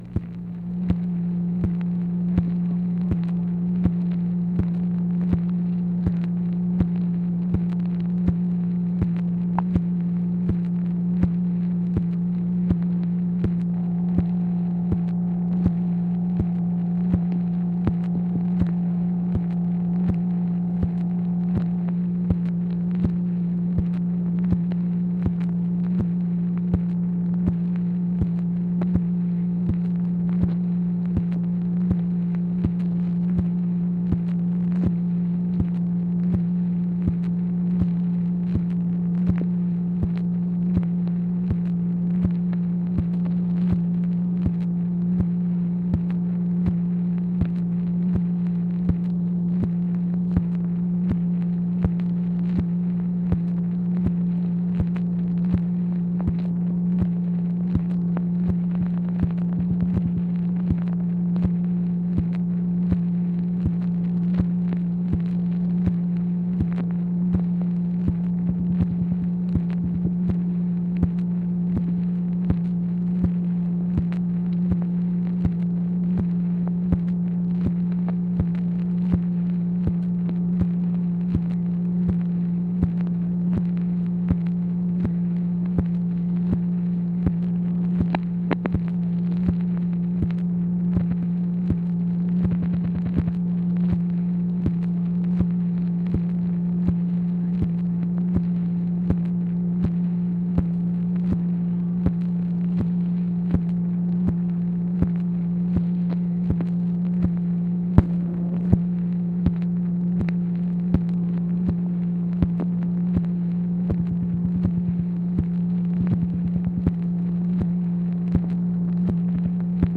MACHINE NOISE, January 21, 1966